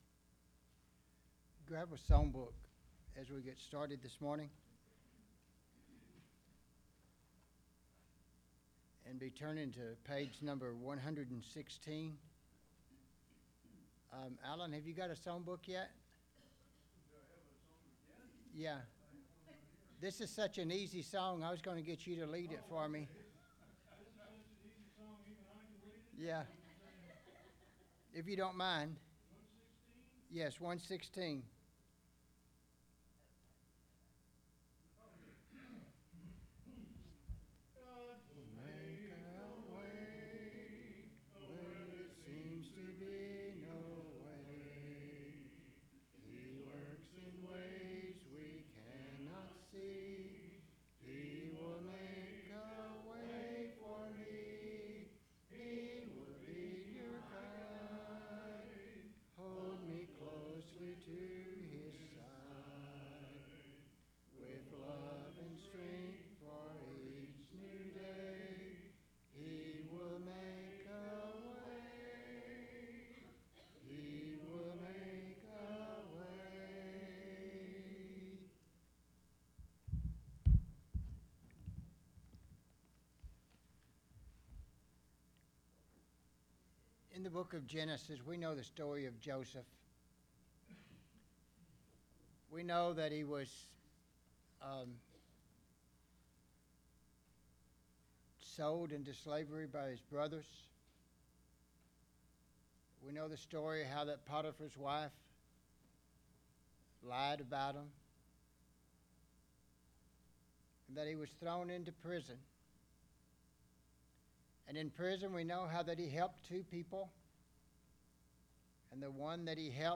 Sunday Bible Class